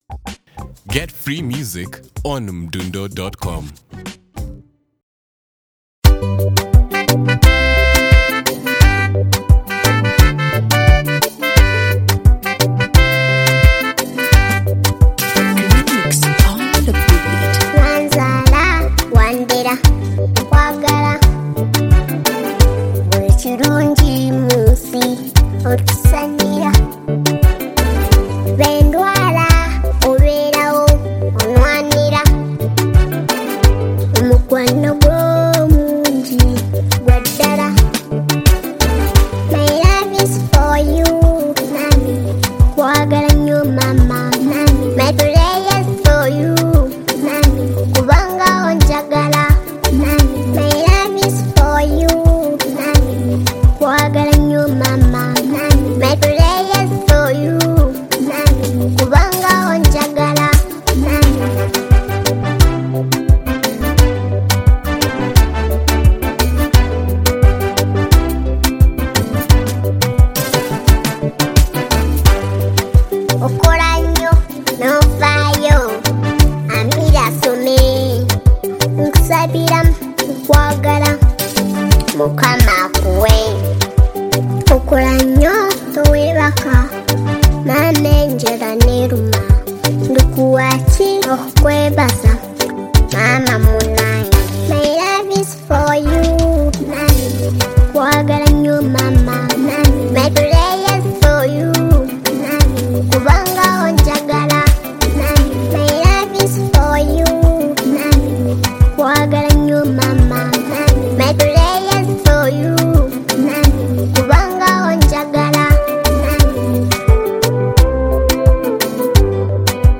Genre: Afro Pop